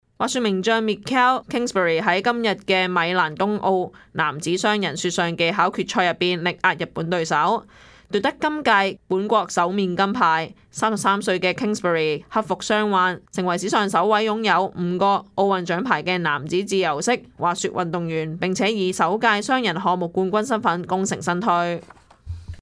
Canada/World News 全國/世界新聞
news_clip_24782.mp3